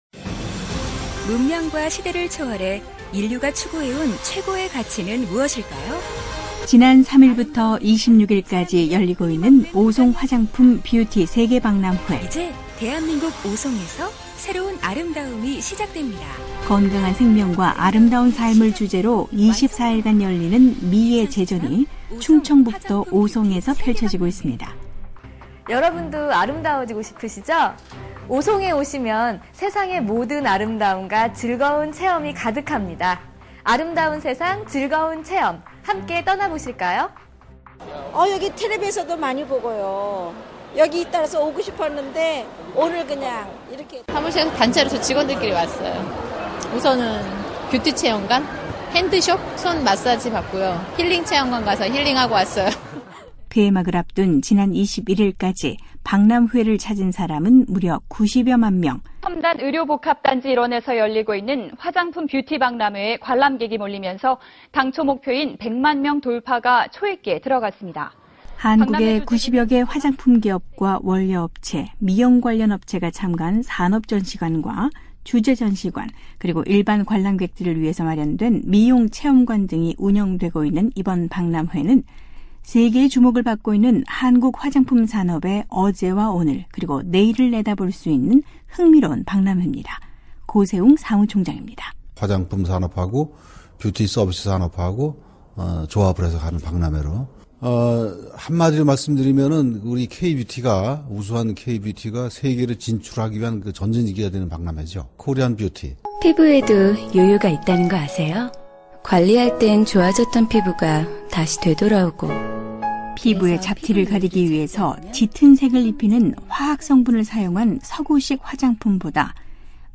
한국 화장품과 미용산업의 모든 것을 한자리에 소개하고 있는 ‘오송 화장품 & 뷰티세계박람회’ 현장으로 가보겠습니다.